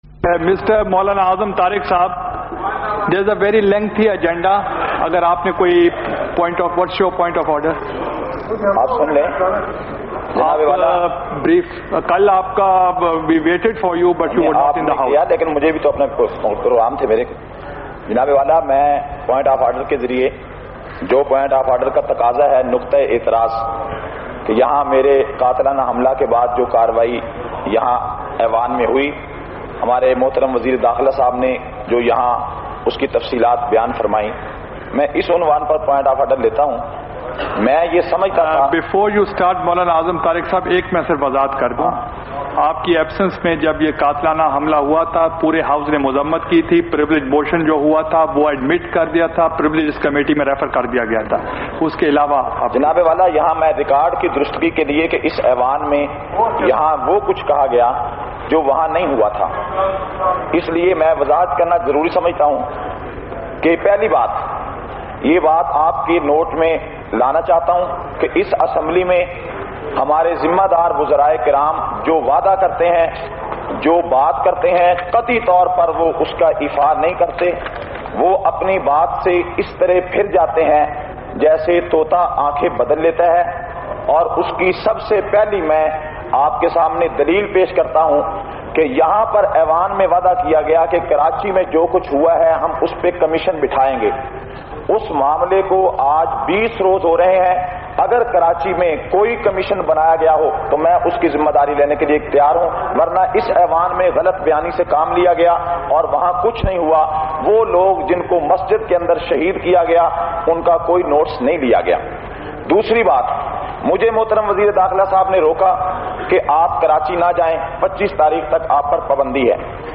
453- Qaomi Assembly Khutbat Vol 7.mp3